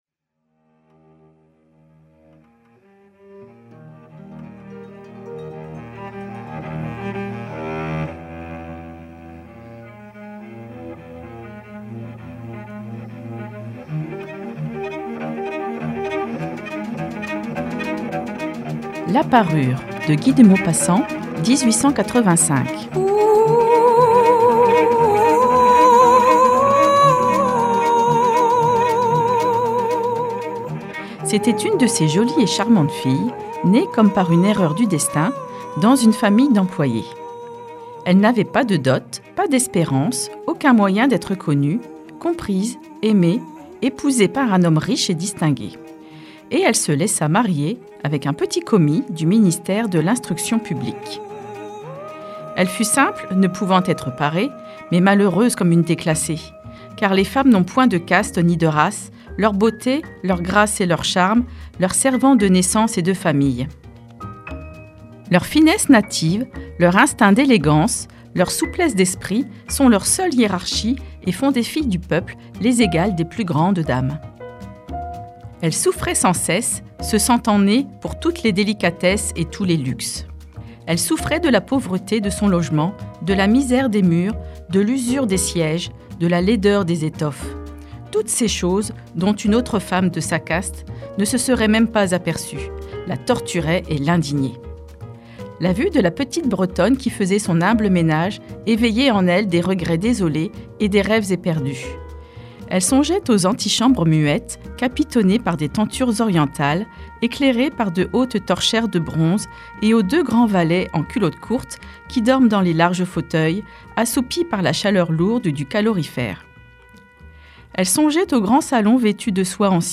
🎧 La parure - Les ateliers de fictions radiophoniques de Radio Primitive